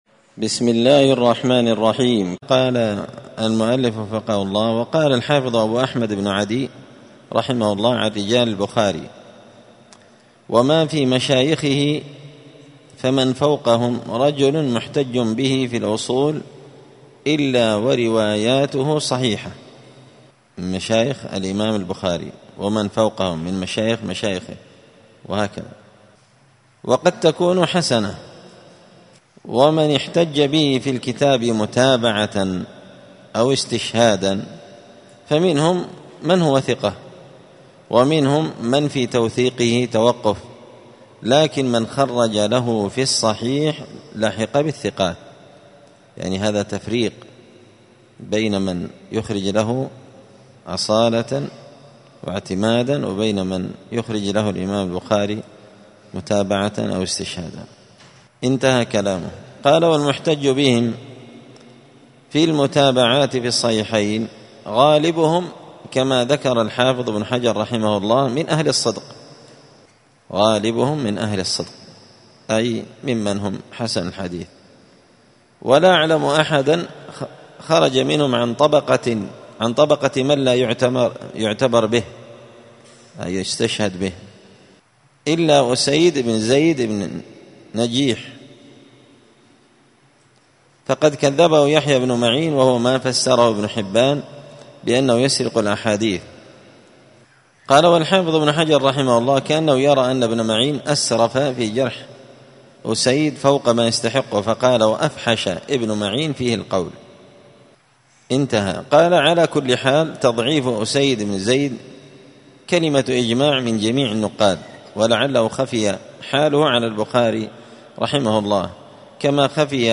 *الدرس الثالث والعشرون (23) تابع لباب المحتج به أصالة ومتابعة*
دار الحديث السلفية بمسجد الفرقان بقشن المهرة اليمن